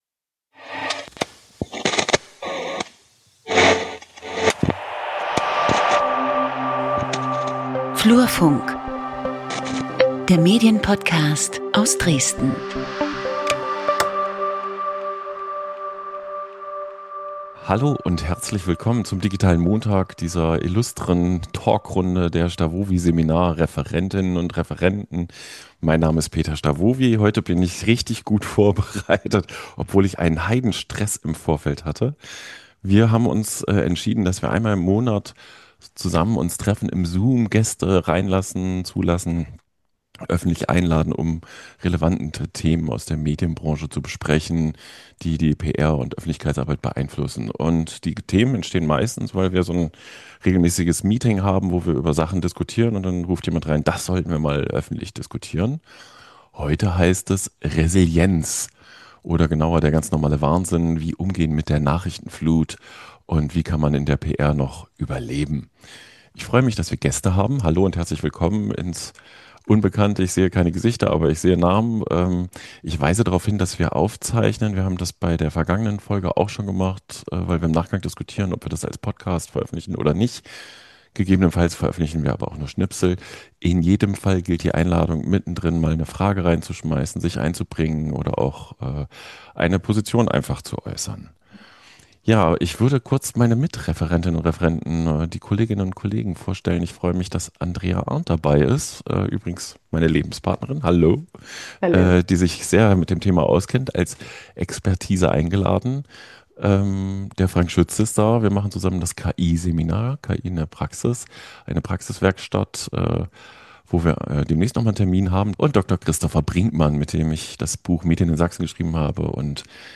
Ausgangspunkt ist ein „Digitaler Mittag“, in dem sich das STAWOWY-Dozententeam und Gäste im Zoom über den „ganz normalen Wahnsinn“ des PR-Alltags austauschen: ständige Erreichbarkeit, Social-Media-Druck, Krisenkommunikation im Minutentakt – und die Frage, wie man trotz allem handlungsfähig bleibt.